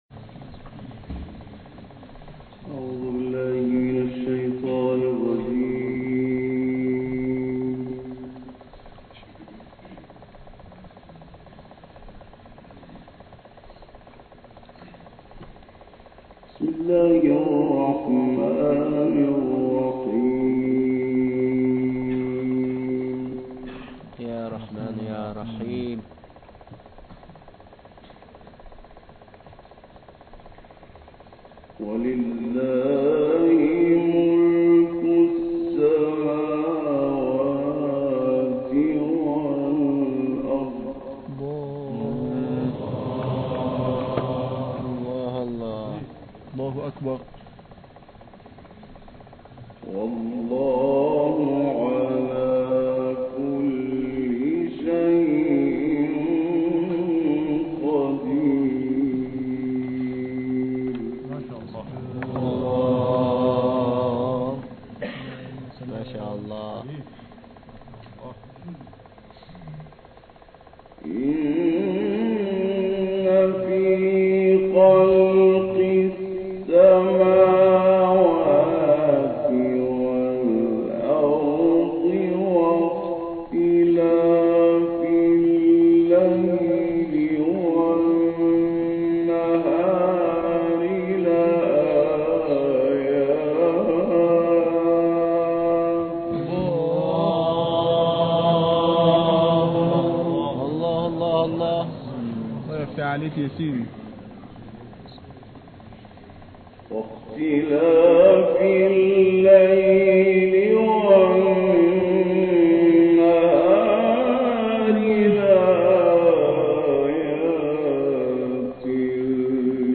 گروه فعالیت‌های قرآنی ــ 28 بهمن 77 سالروز تلاوت به یادماندنی مرحوم استاد لیثی در دانشگاه فارابی ارتش است؛ روزهایی که هنوز گوش موسیقایی برای تلاوت، اسیر روزمرگی نبود.